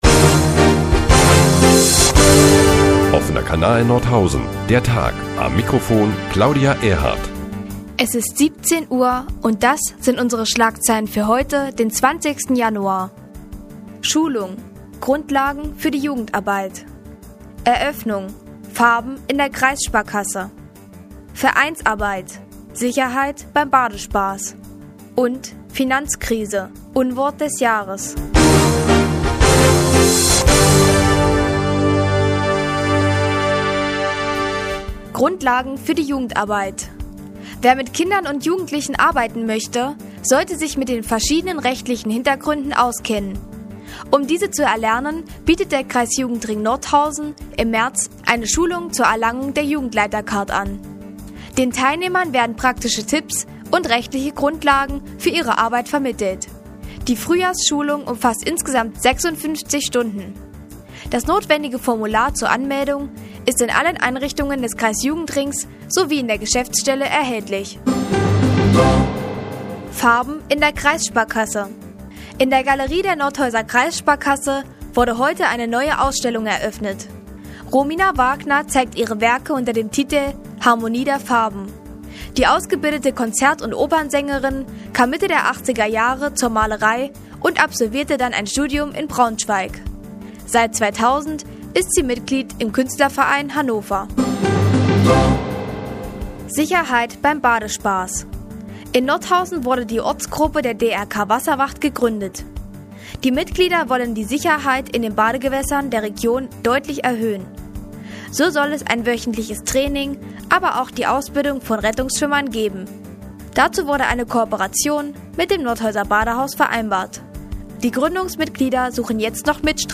20.01.2009, 15:30 Uhr : Die tägliche Nachrichtensendung des OKN ist nun auch in der nnz zu hören. Heute unter anderem mit einer Schulung zur Erlangung einer Jugendleitercard und einer neuen Ausstellung in der Nordhäuser Kreissparkasse.